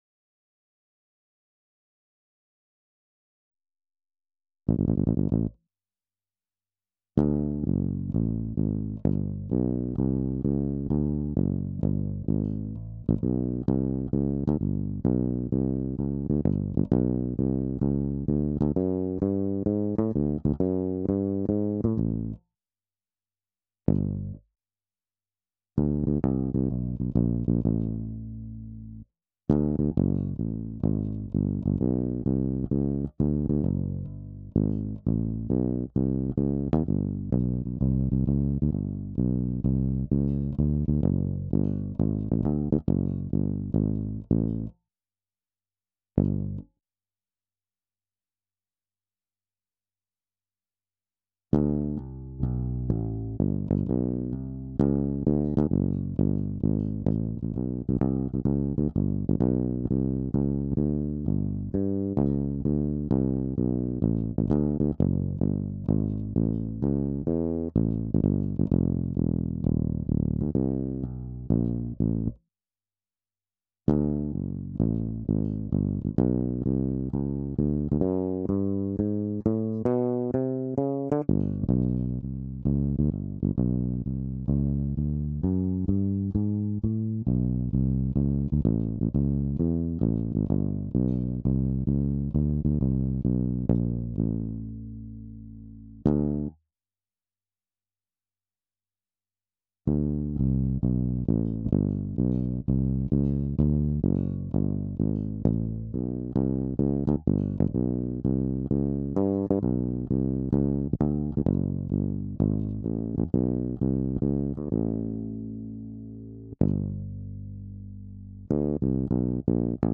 12_Bass.wav